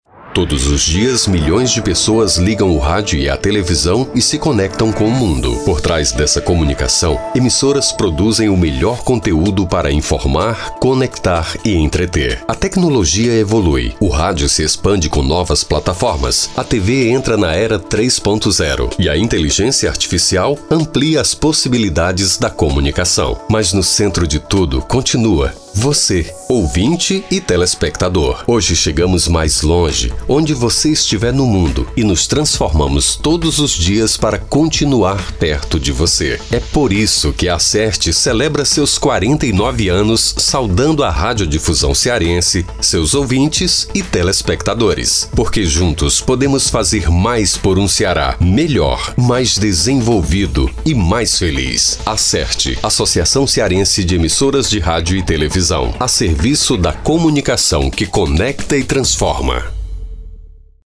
SEGUE O SPOT COMEMORATIVO, QUE PODERÁ SER VEICULADO PELAS EMISSORAS DURANTE TODA ESTA SEMANA.